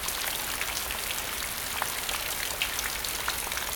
rainpuddle.ogg